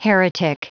Prononciation du mot heretic en anglais (fichier audio)
Prononciation du mot : heretic